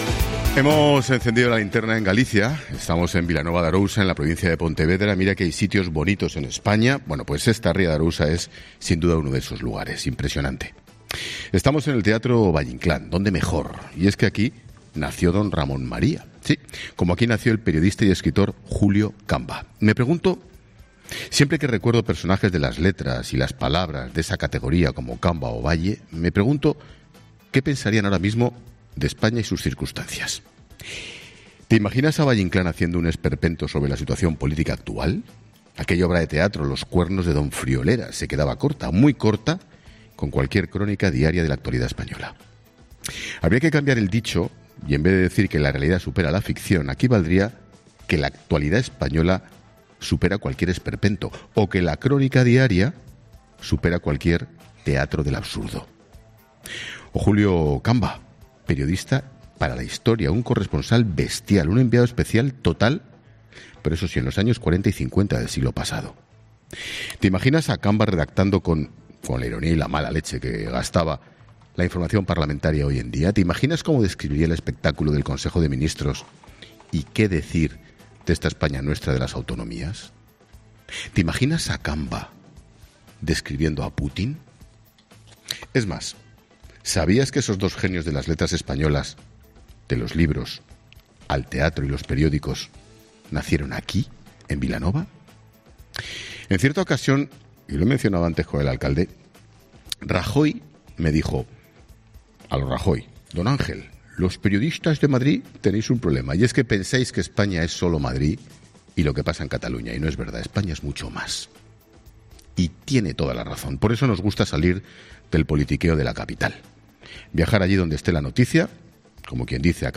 Monólogo de Expósito
El director de 'La Linterna', Ángel Expósito, lanza múltiples reflexiones desde Vilanova de Arousa
Estamos en el teatro Valle Inclán.